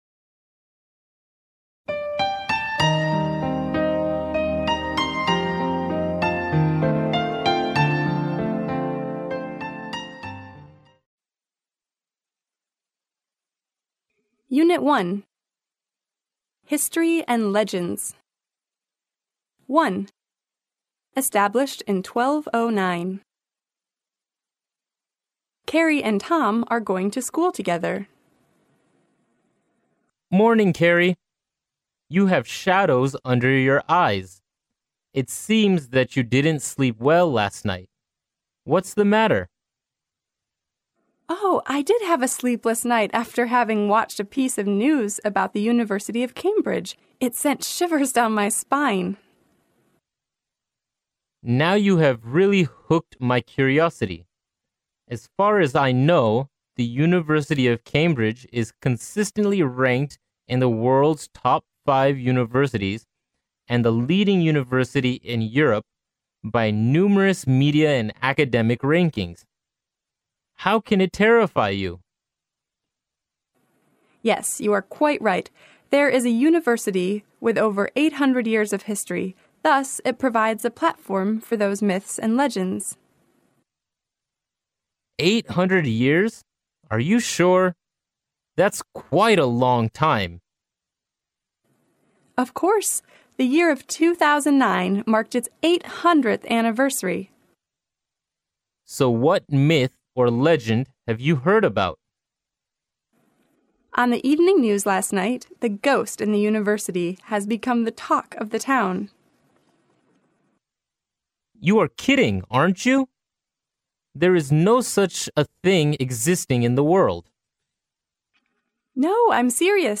剑桥大学校园英语情景对话01：追溯剑桥（mp3+中英）